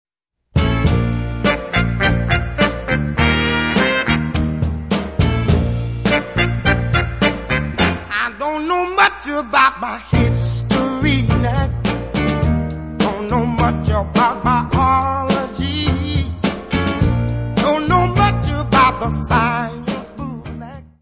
: stereo; 12 cm
Music Category/Genre:  Jazz / Blues